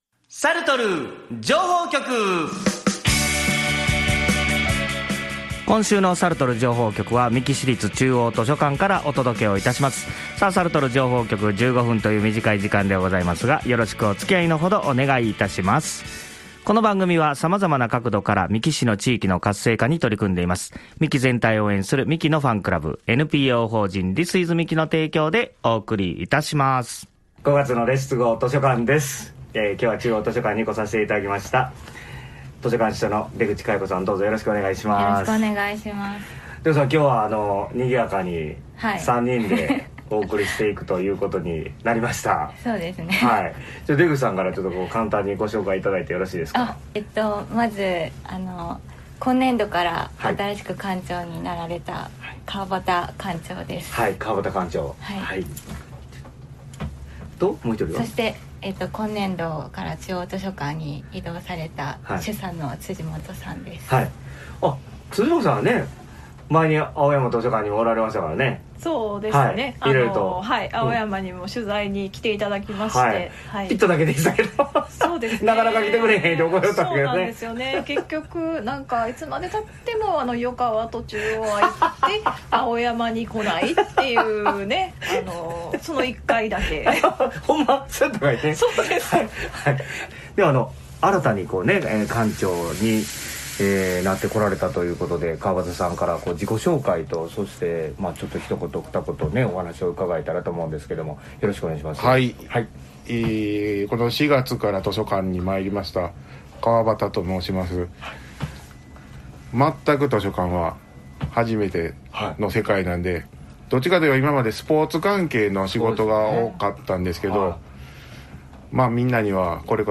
月一、三木市の図書館の情報をお届けするコーナー『Let’s go 図書館』は、さるとる情報局（水曜日16:35〜）で放送することとなりました。